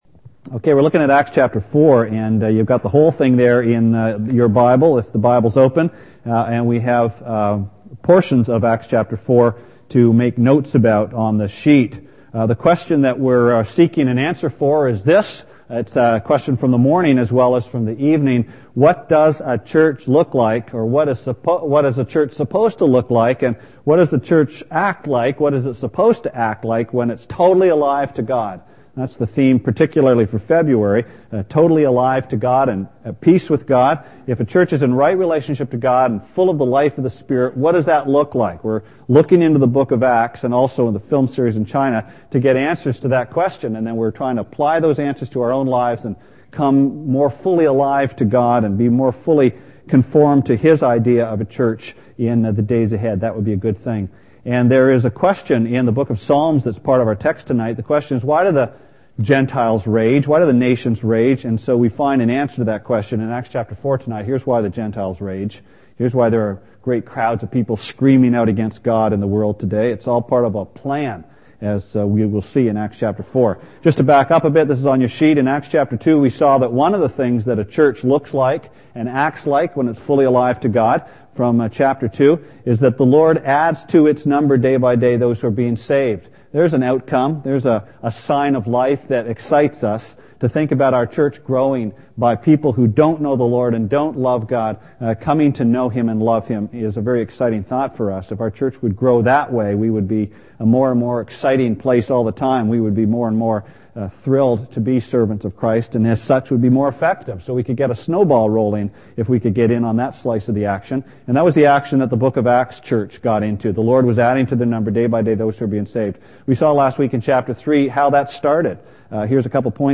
Sermon Archives Feb 8
Part 4 of a Sunday evening series.